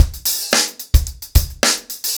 TupidCow-110BPM.51.wav